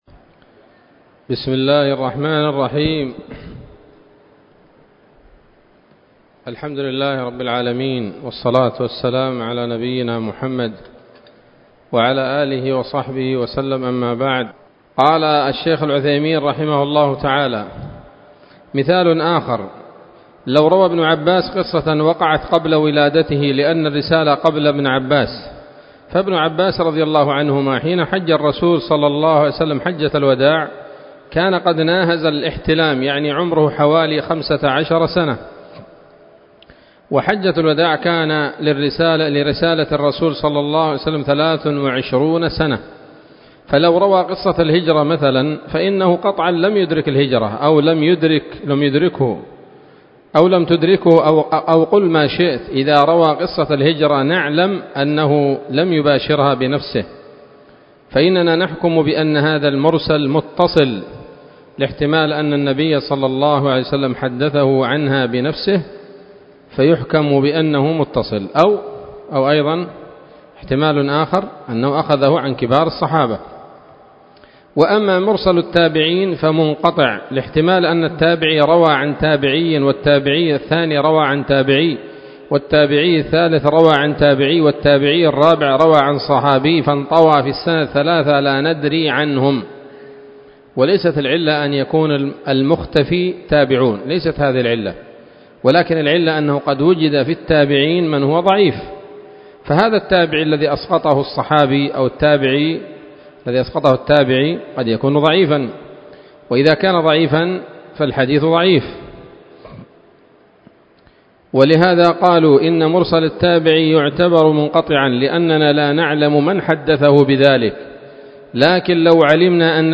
الدرس التاسع والخمسون من شرح نظم الورقات للعلامة العثيمين رحمه الله تعالى